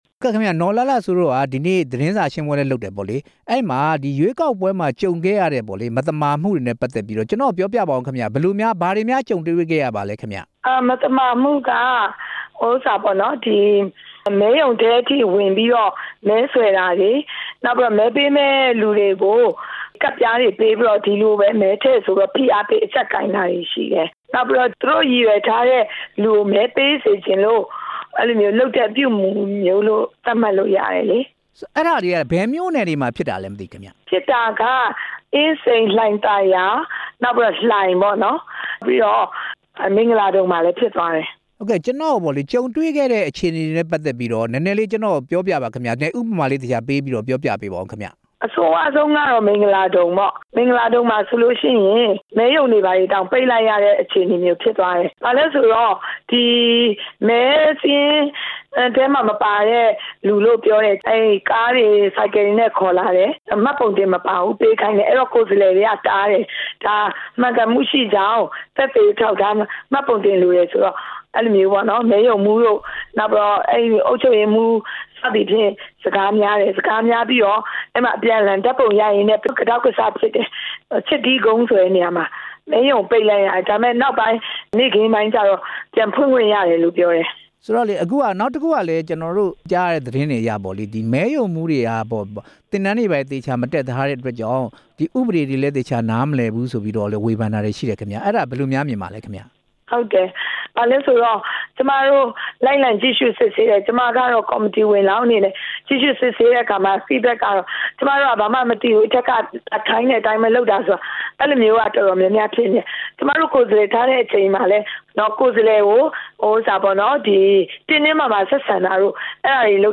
စည်ပင်ရွေးကောက်ပွဲ ရွေးကောက်ခံ ကိုယ်စားလှယ် နော်လှလှစိုး နဲ့ မေးမြန်းချက်